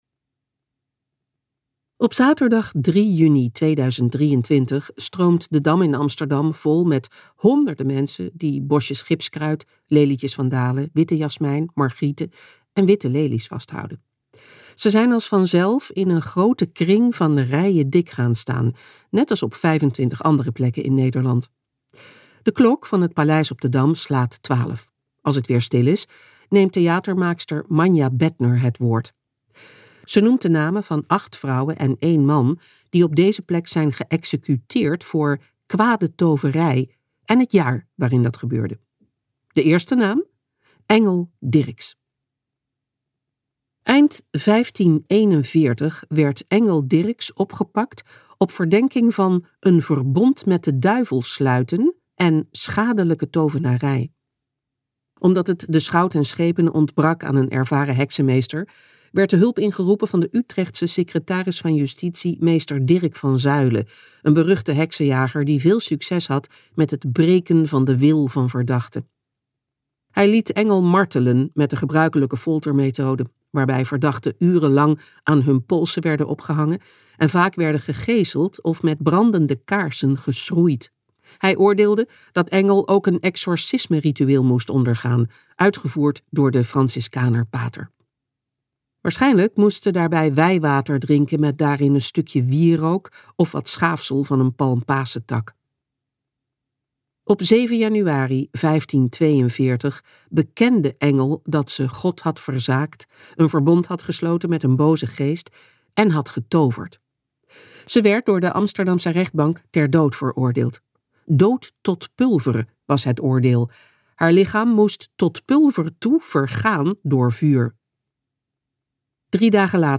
Ambo|Anthos uitgevers - Heksen luisterboek